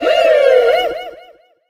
tara_kill_vo_04.ogg